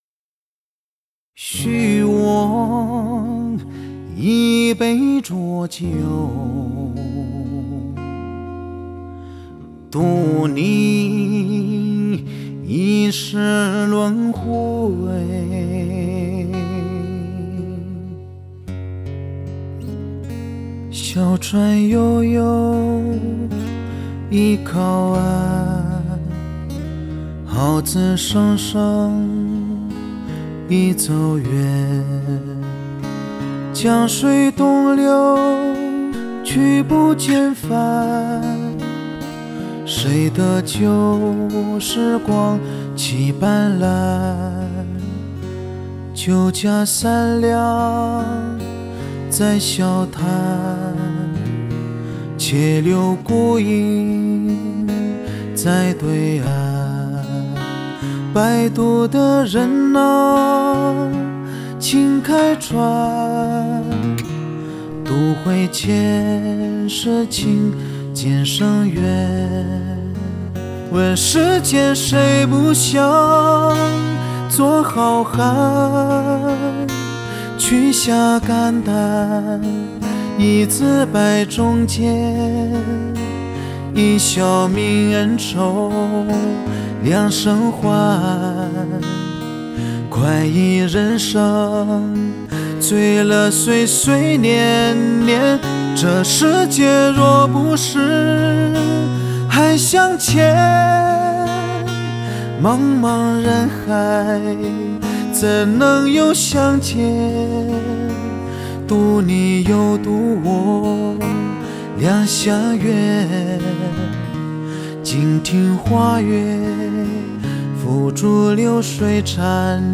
两句戏腔“许我一杯浊酒/渡你一世轮回”代入。